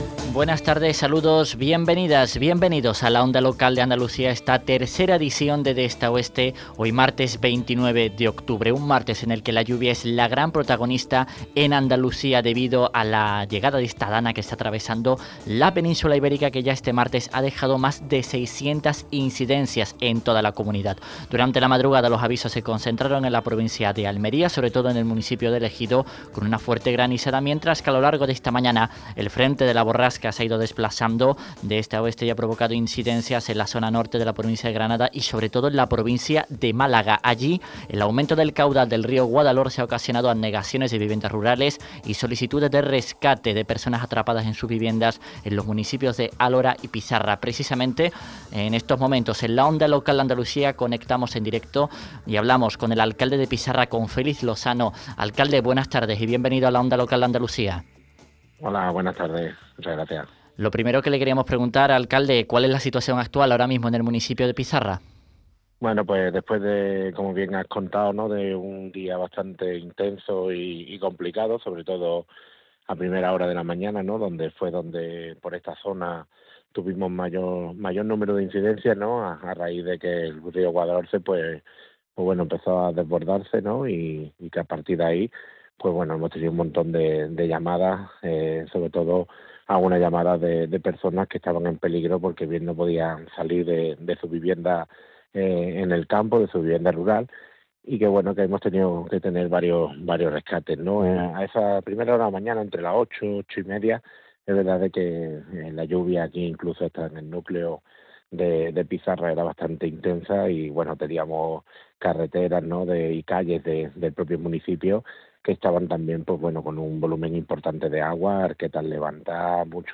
En la Onda Local de Andalucía, hemos hablado con el alcalde Pizarra, Féliz Lozano sobre los últimos daños.
FÉLIZ LOZANO – ALCALDE DE PIZARRA